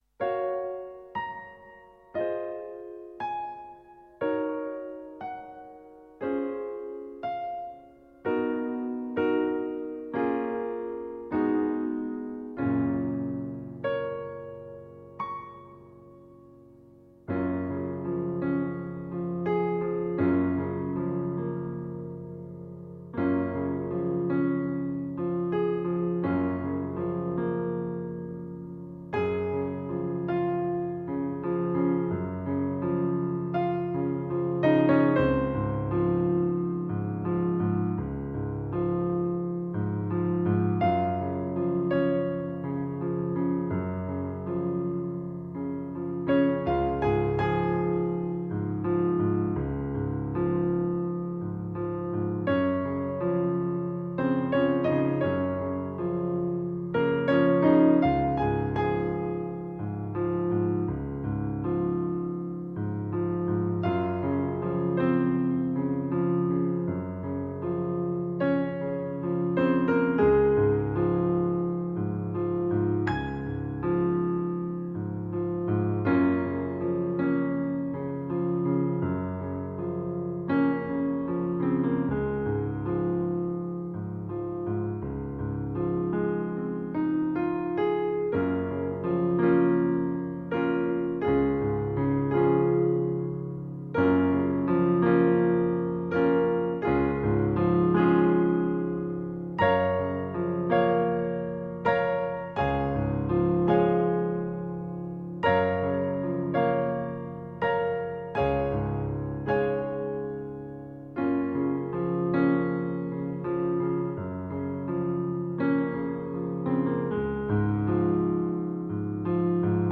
described the album as experiencing “songs without words”.